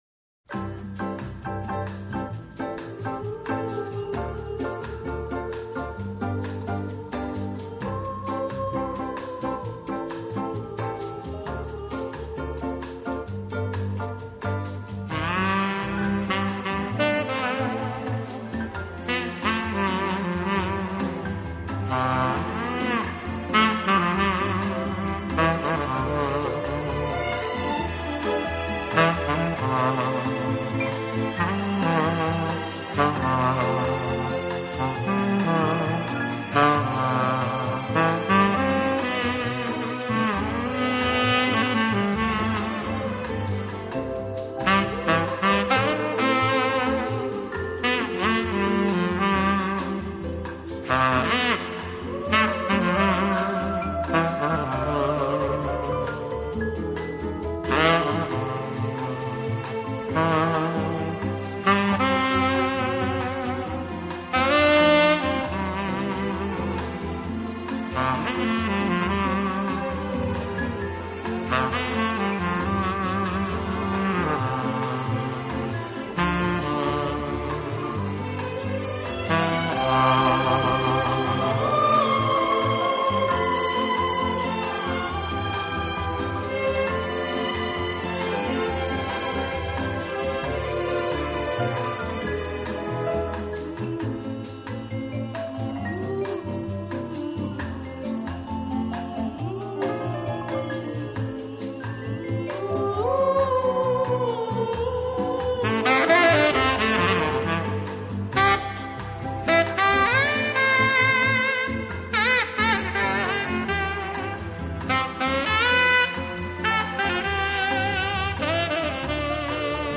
音乐风格：Jazz/Instrumental
及其它类型音乐中，表现出杰出的抒情，浪漫风格普遍受到人们的喜爱。